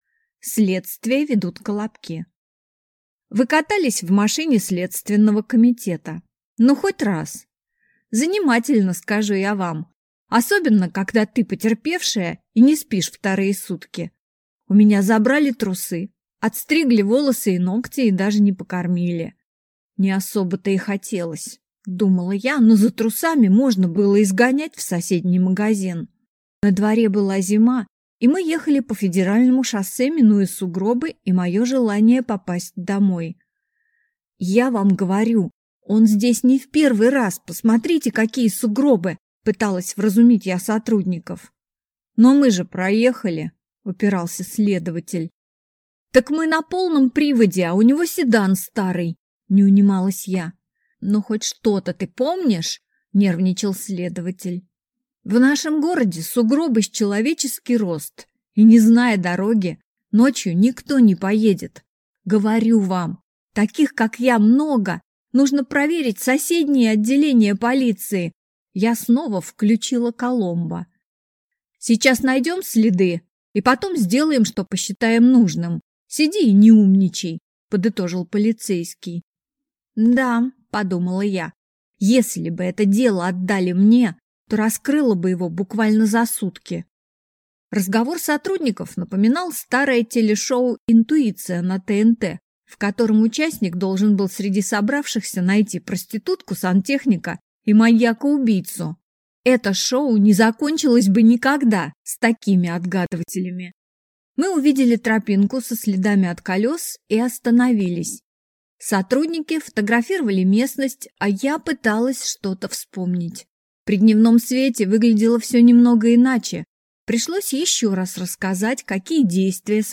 Аудиокнига Я – оптимист. Часть 2. Юность | Библиотека аудиокниг